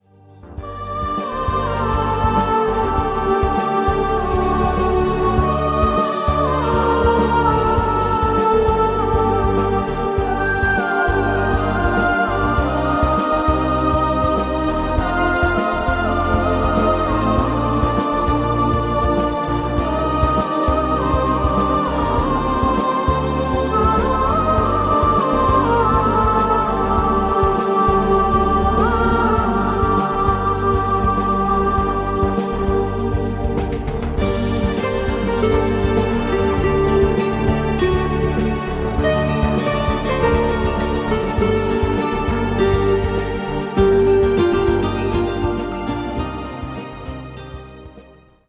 CHILLOUT LOUNGE MUSIC